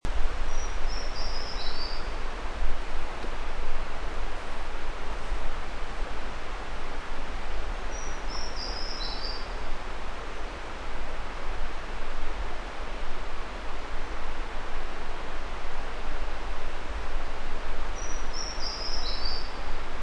28-5自忠特富野2012mar26麟胸鷦鷯3.mp3
物種名稱 臺灣鷦眉 Pnoepyga formosana
錄音地點 嘉義縣 阿里山 自忠特富野
10 錄音環境 人工林 發聲個體 行為描述 鳥叫 錄音器材 錄音: 廠牌 Denon Portable IC Recorder 型號 DN-F20R 收音: 廠牌 Sennheiser 型號 ME 67 標籤/關鍵字 備註說明 MP3檔案 28-5自忠特富野2012mar26麟胸鷦鷯3.mp3